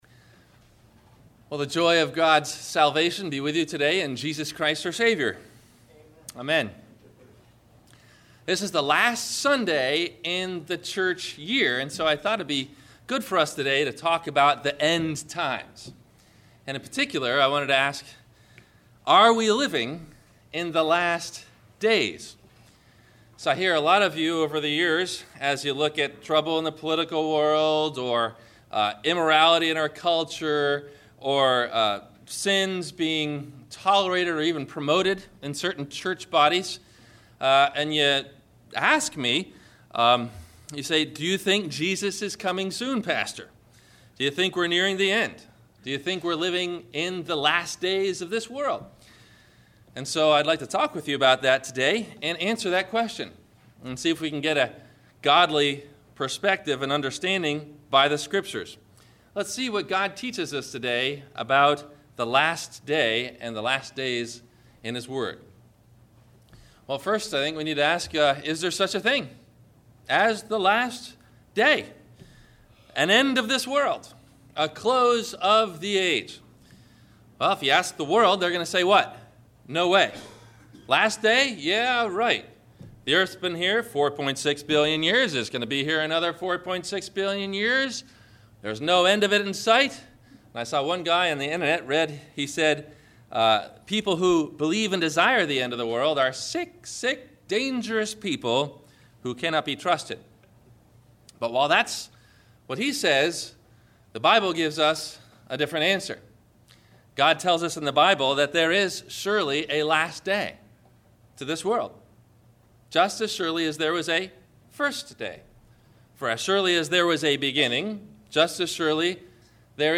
Are We In The Last Of Days? – Sermon – November 25 2012